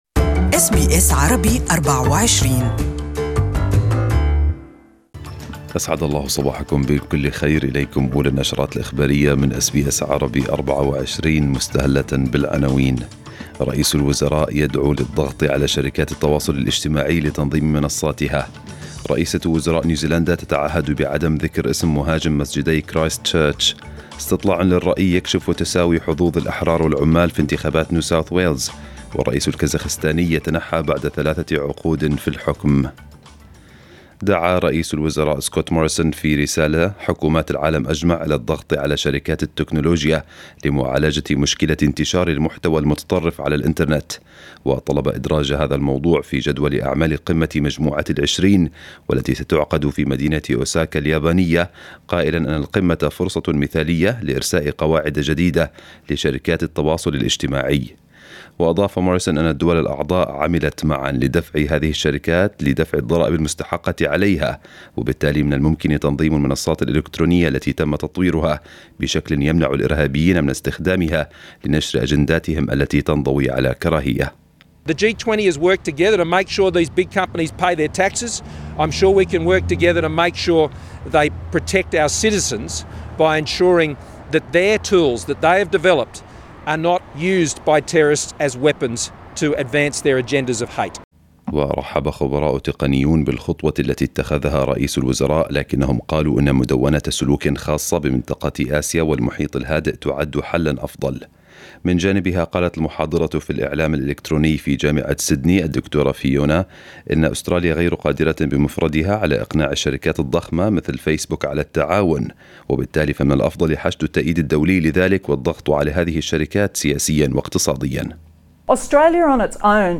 نشرة الأخبار باللغة العربية لهذا الصباح